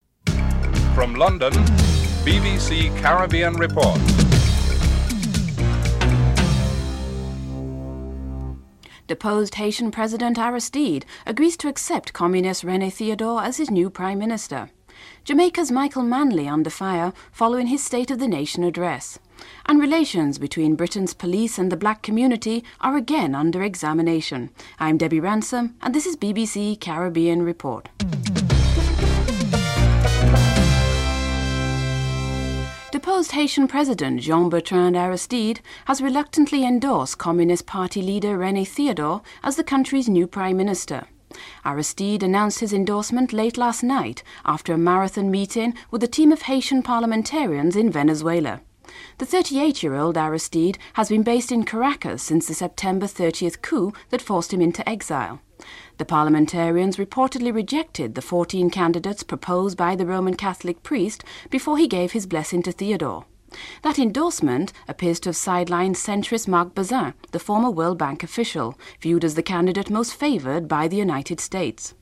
1. Headlines (00:00-00:32)
3. Interview with Marc Bazin, U.S. suported candidate for Haitian leadership (01:18-02:53)